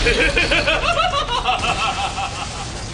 نغمة شخص يضحك بقوة
funny , best , nokia , crazy , 2013 , iphone5 , laughing ,